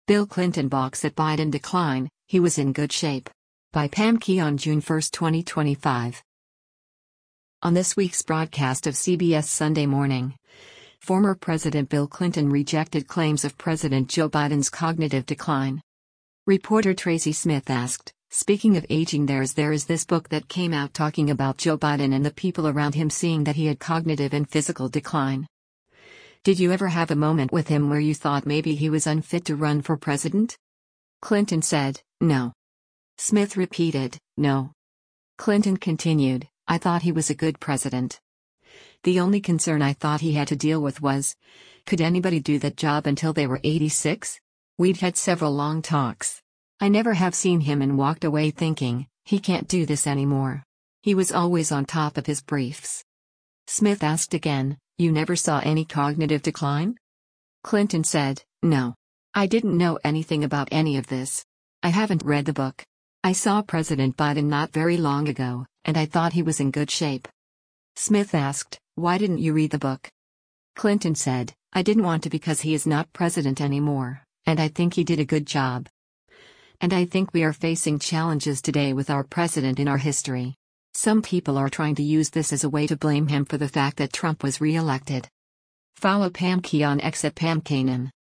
On this week’s broadcast of “CBS Sunday Morning,” former President Bill Clinton rejected claims of President Joe Biden’s cognitive decline.